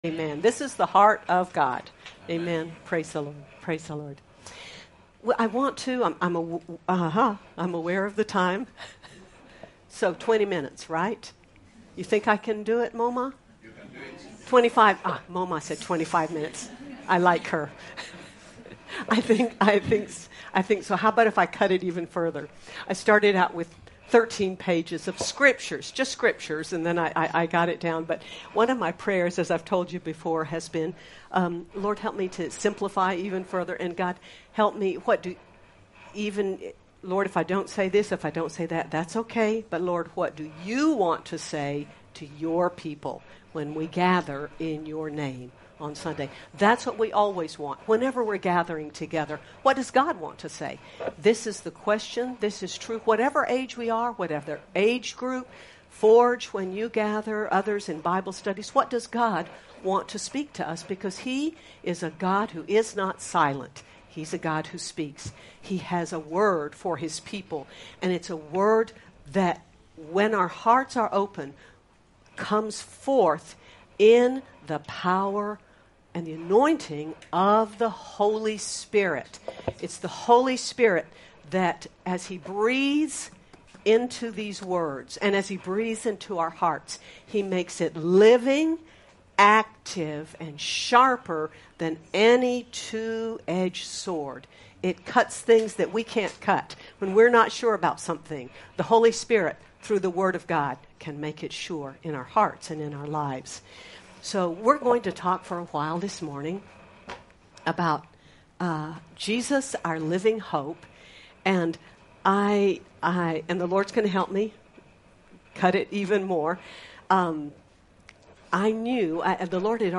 Dec 07, 2025 Jesus, Our Living Hope MP3 SUBSCRIBE on iTunes(Podcast) Notes Discussion Sermons in this Series On the first Sunday of Advent, we take time to consider Jesus, our Living Hope. Sermon by